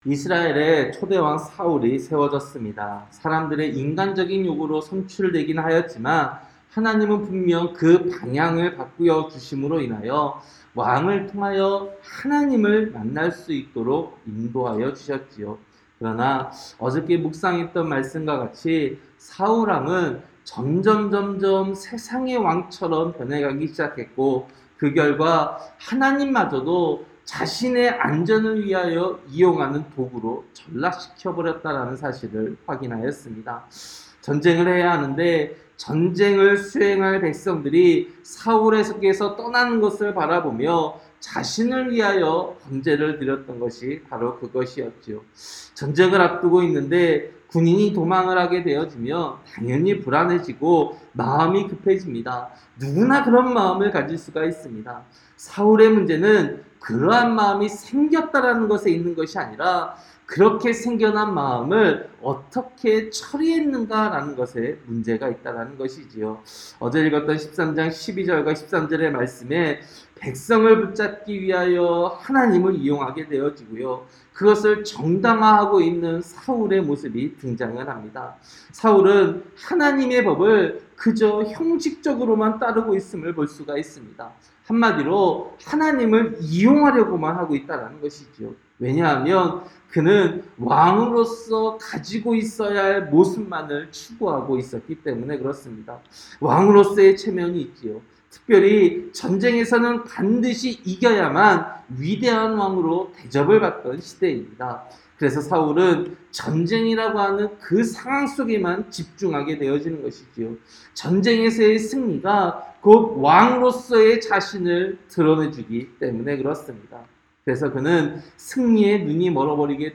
새벽설교-사무엘상 14장